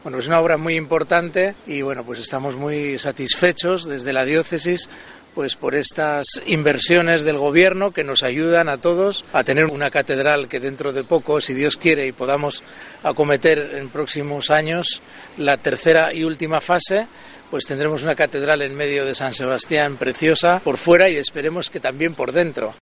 Fernando Prado, obispo de San Sebastián